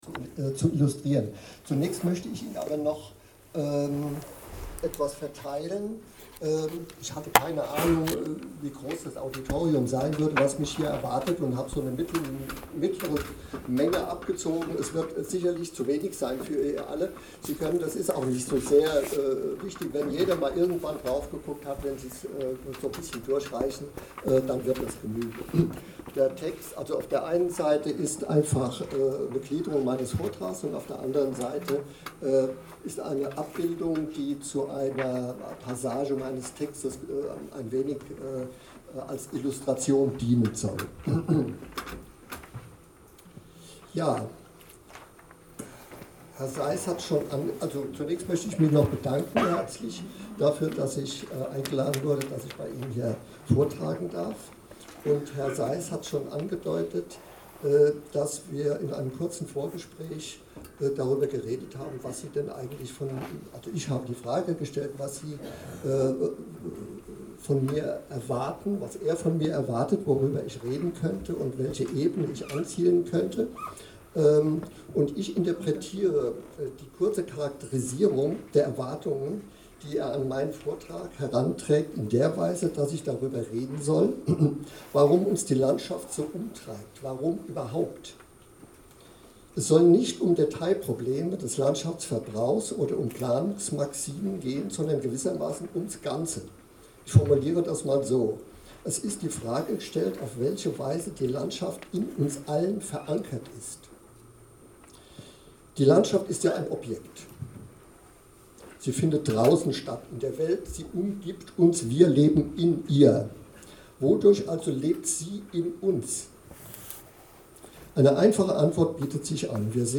Answers and Impressions from the 6th Area Planning Symposium